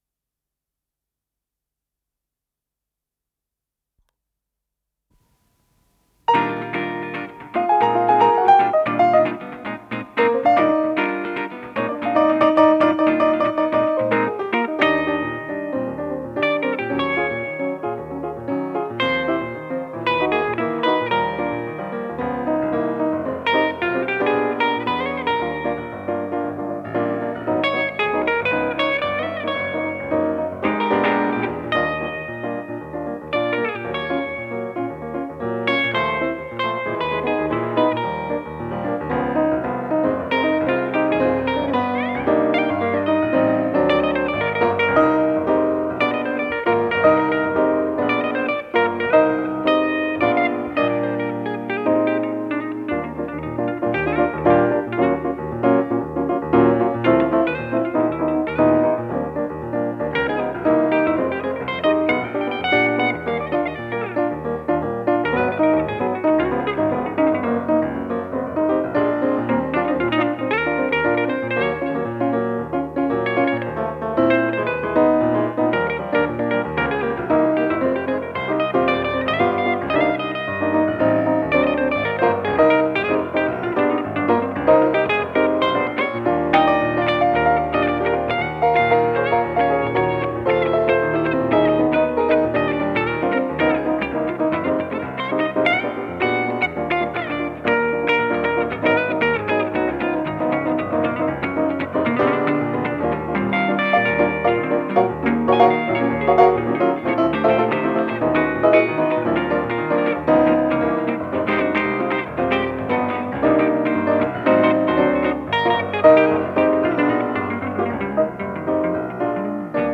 с профессиональной магнитной ленты
фортепиано
электрогитара
ВариантДубль моно